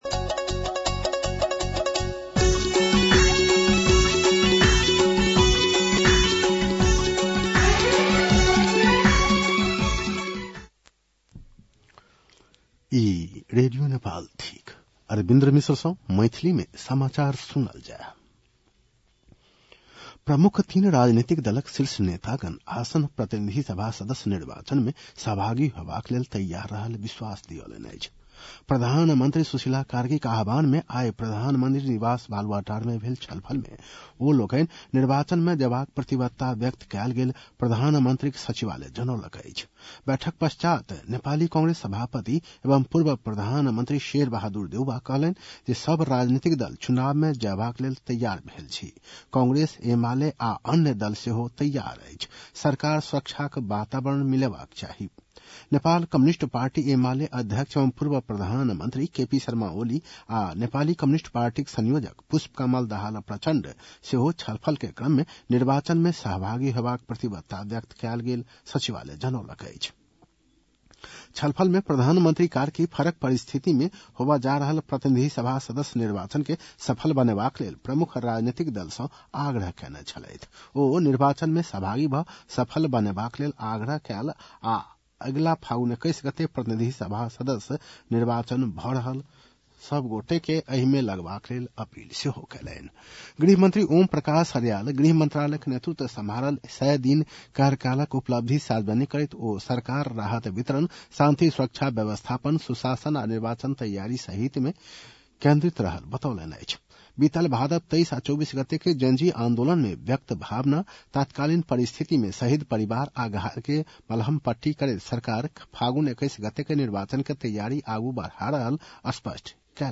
मैथिली भाषामा समाचार : १२ पुष , २०८२
6.-pm-maithali-news-1-5.mp3